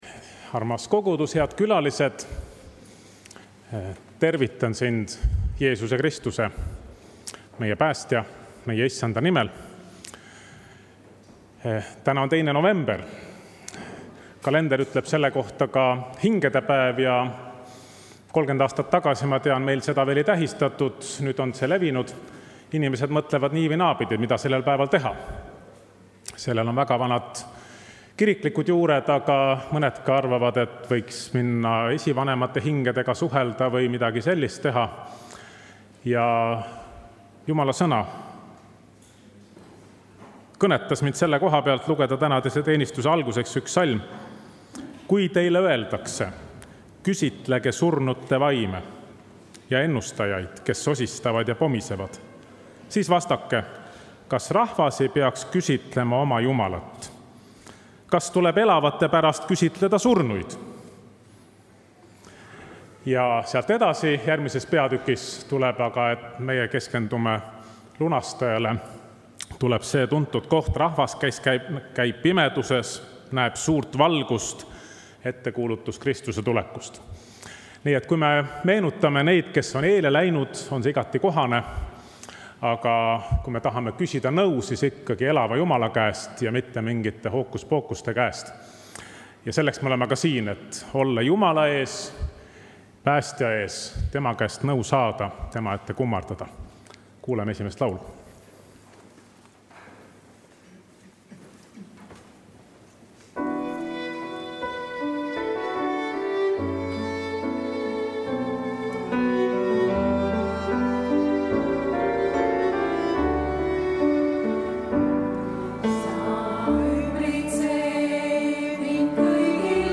Pühakirja lugemine ja palve
Jutlus